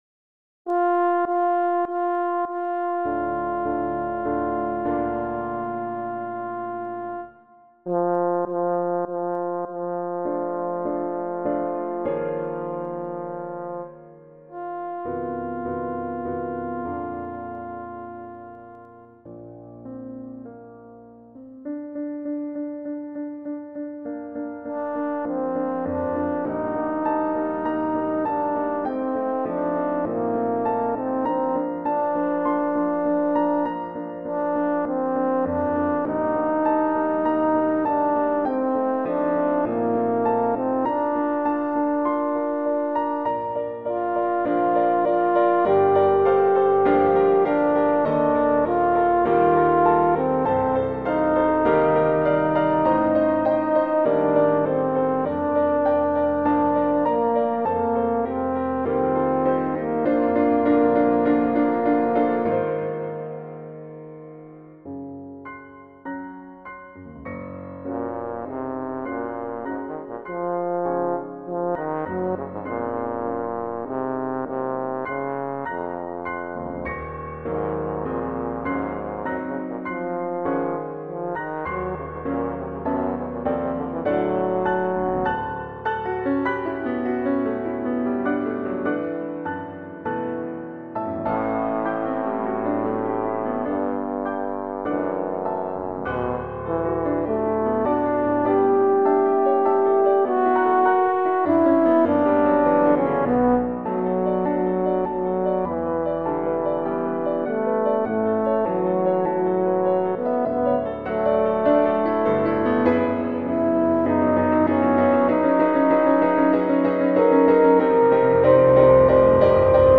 arranged for Horn in F and Piano.
for Horn in F and Piano.